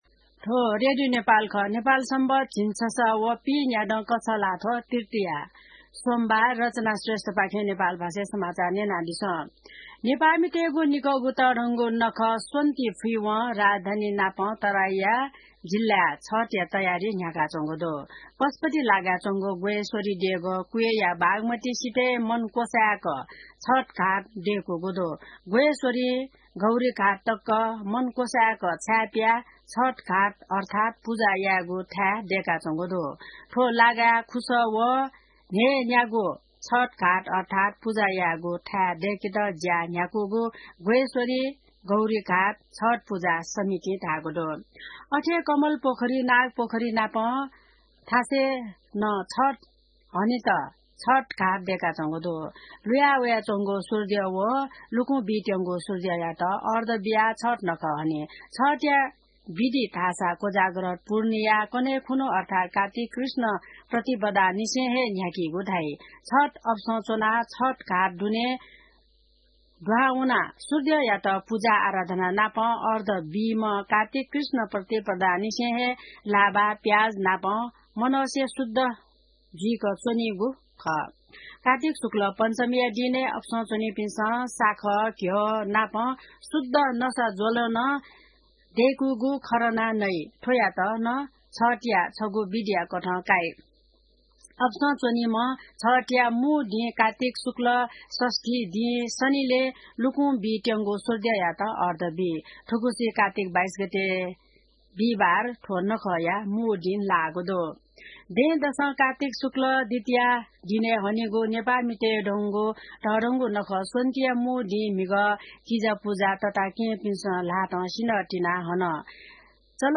नेपाल भाषामा समाचार : २० कार्तिक , २०८१